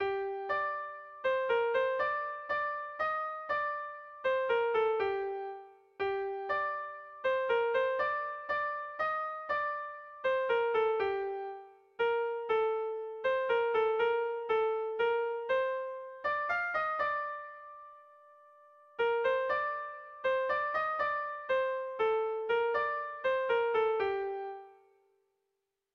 Erlijiozkoa
Zortziko txikia (hg) / Lau puntuko txikia (ip)
AABD